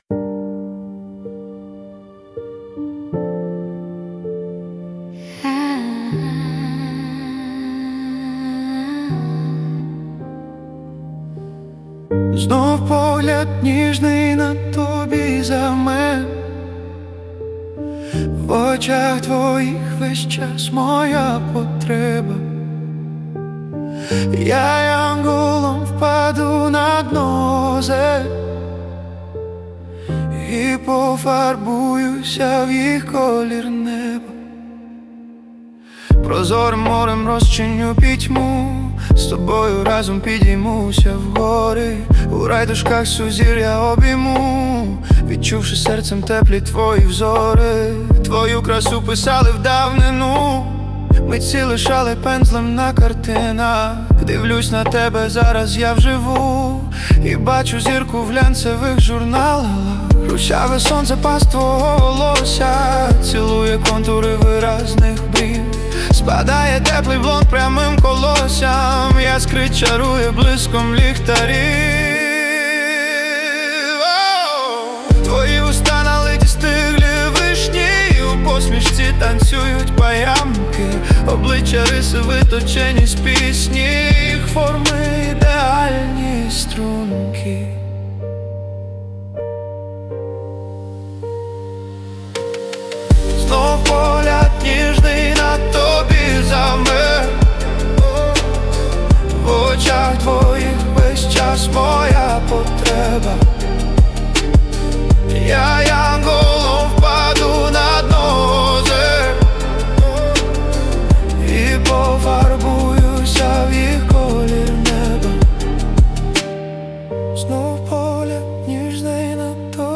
Музика і вокал ШІ - SUNO AI v4.5+
СТИЛЬОВІ ЖАНРИ: Ліричний
ВИД ТВОРУ: Пісня